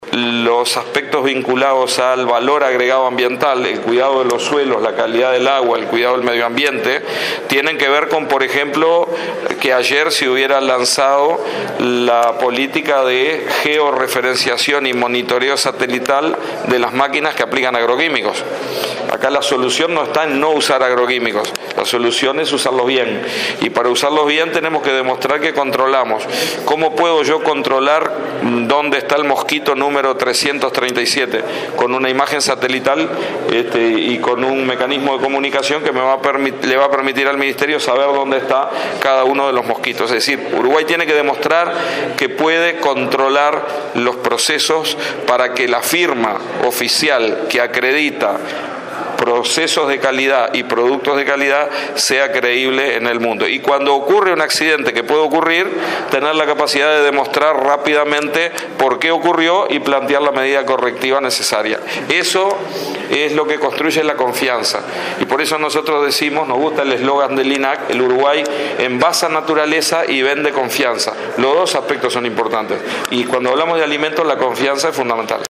Desde el 1.° de setiembre es obligación que todas las empresas y productores aplicadores de productos fitosanitarios incluyan en sus equipos un instrumento de geolocalización, asì lo exige el Ministerio de Ganadería.”Se trata de un dispositivo de monitoreo que indica dónde está el equipo y en qué momento está aplicando”, anunció el ministro Tabaré Aguerre en la jornada previa al Consejo de Ministros en Canelones.
aguerre.mp3